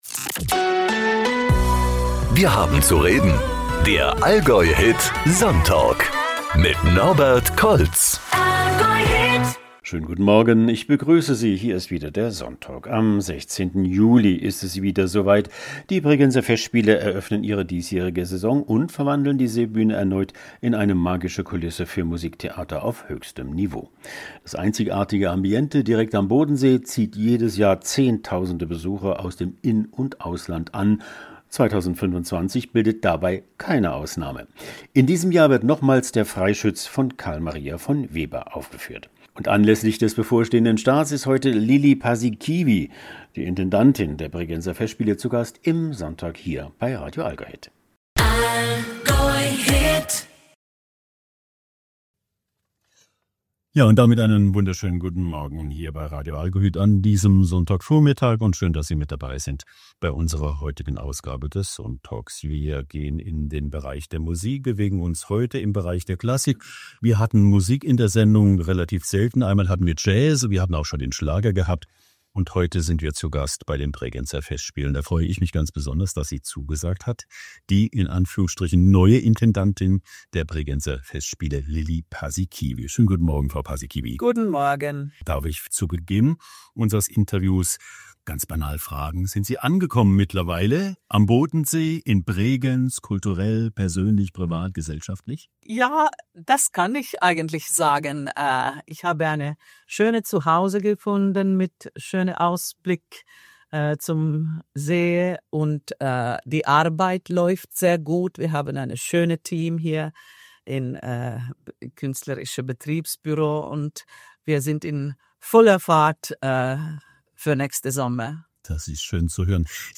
Lilli Paasikivi, die Intendantin der Bregenzer Festspiele, ist am Sonntag, 22. Juni 2025, von 10 bis 12 Uhr zu Gast im „SonnTalk“ bei Radio AllgäuHIT.